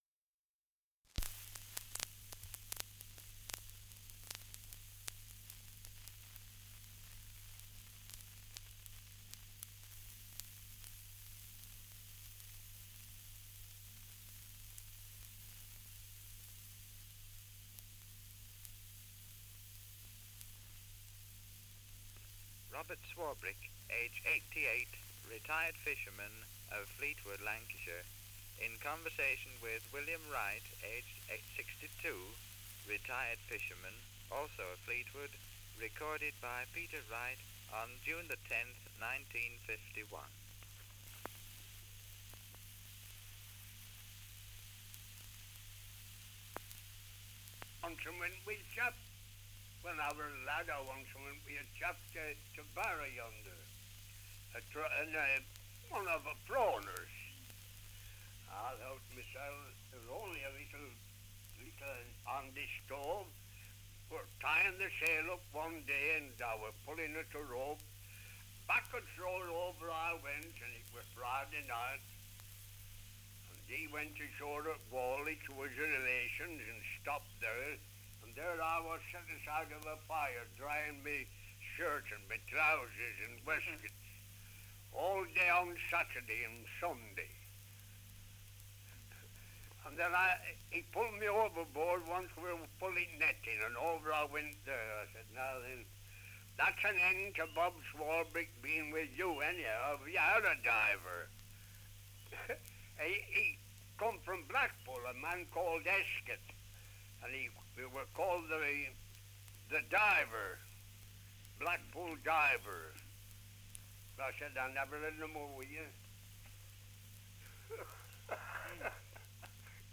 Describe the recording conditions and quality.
78 r.p.m., cellulose nitrate on aluminium